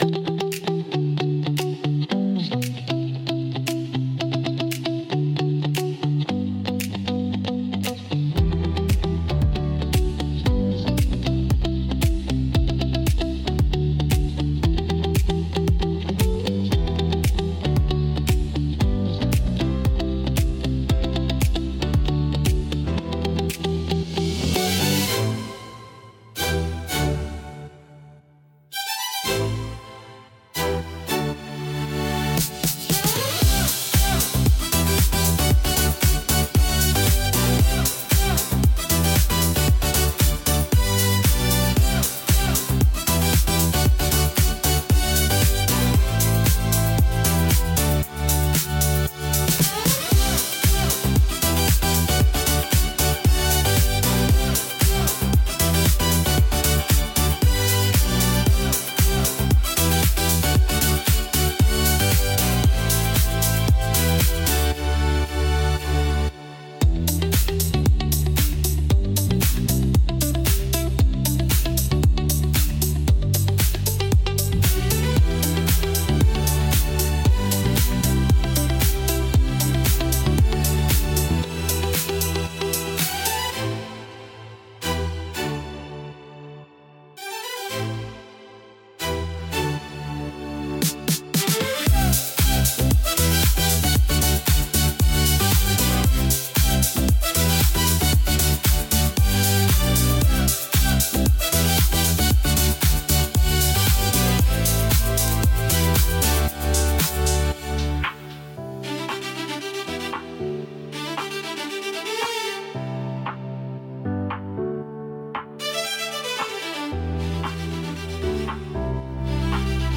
聴く人の集中を妨げず、信頼感や安定感を演出するジャンルです